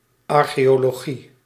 Ääntäminen
UK : IPA : /ˌɑː(ɹ).kiˈɒl.ə.dʒi/ US : IPA : /ˌɑɹ.kiˈɑl.ə.dʒi/